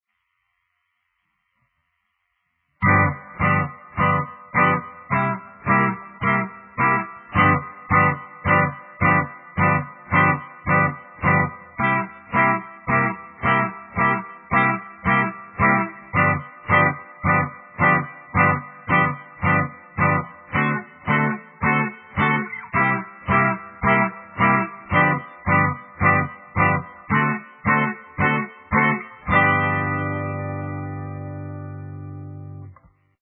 12 Bar Blues in G
shuffle rhythm in g key